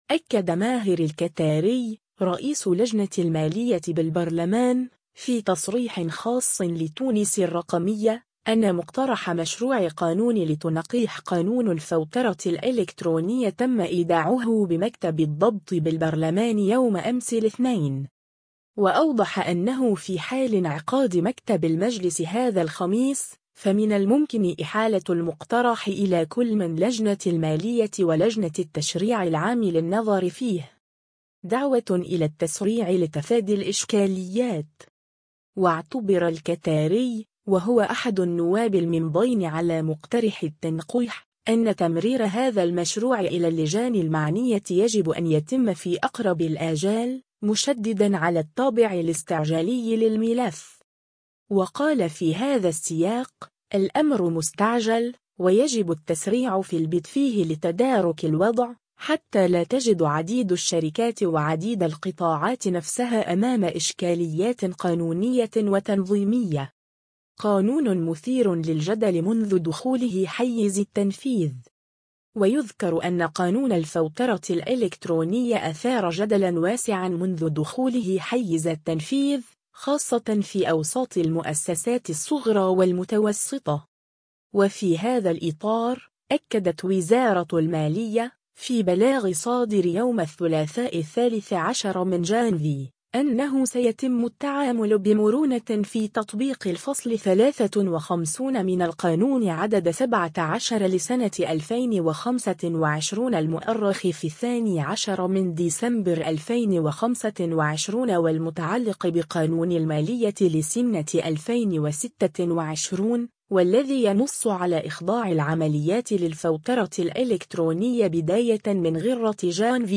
أكد ماهر الكتاري، رئيس لجنة المالية بالبرلمان، في تصريح خاص لـ”تونس الرقمية”، أنّ مقترح مشروع قانون لتنقيح قانون الفوترة الإلكترونية تم إيداعه بمكتب الضبط بالبرلمان يوم أمس الاثنين.